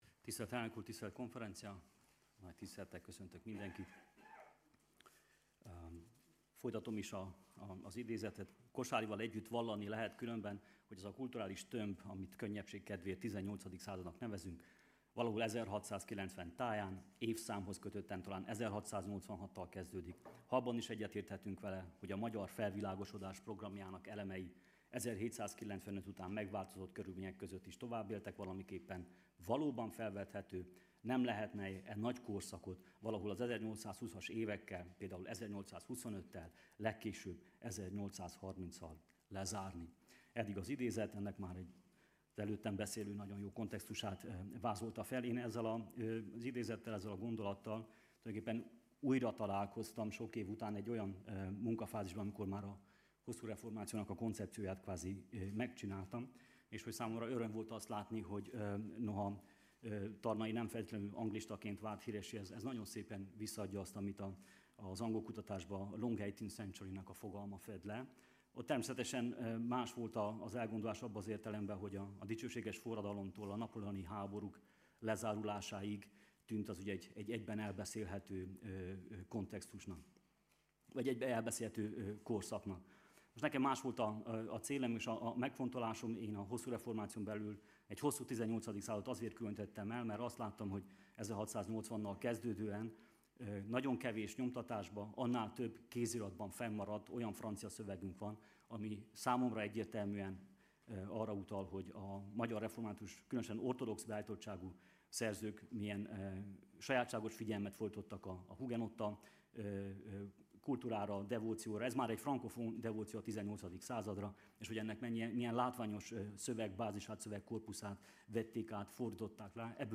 Az előadás Tarnai Andor javaslatát alapul véve az úgynevezett hosszú 18. század korszakalakzatát vizsgálja meg európai példákból kiindulva és ezek lehetséges